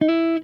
SLIDESOLO4.wav